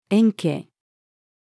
円形-female.mp3